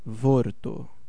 Ääntäminen
US : IPA : [wɝd] UK : IPA : /wɜː(ɹ)d/